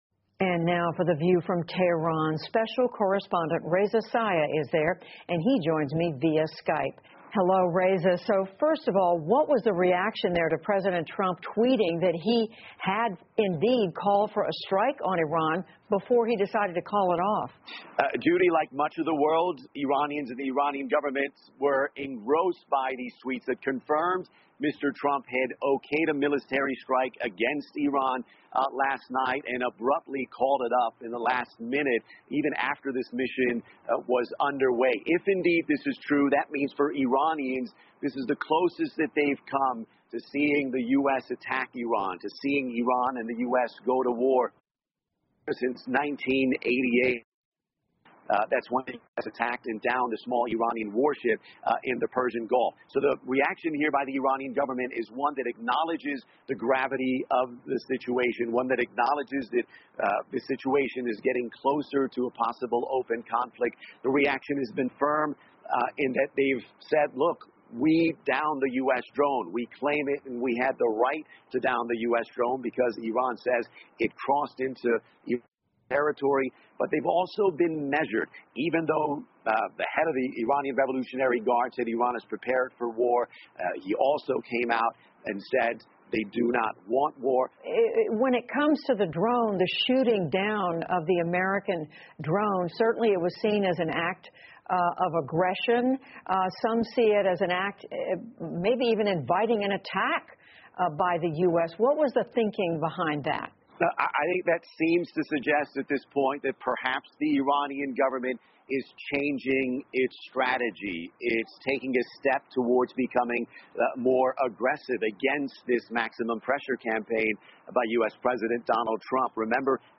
PBS高端访谈:特朗普在最后时刻取消军事行动 听力文件下载—在线英语听力室